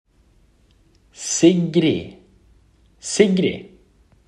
Pronunciation of the name “Sigrid.”
1. The first two letters, “Si”, sound similar to the “si” in the English word “sit.”
2. ‘”G” is pronounced as a hard “g,” like in the word “goat.”
3. The letter “r” is rolled, followed by an “i” that is produced like the “ee” sound in English.
4. The final letter “d” is usually silent.
Sigrid.mp3